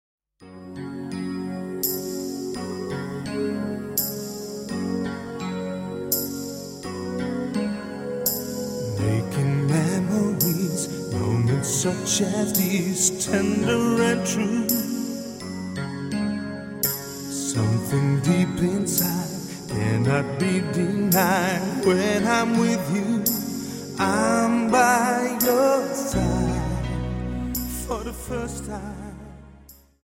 Dance: Slow Waltz 28 Song